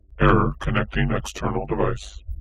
computer connected device error failed robot space spaceship sound effect free sound royalty free Memes